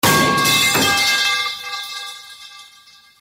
Metal Pipe Sound Effect Free Download
Metal Pipe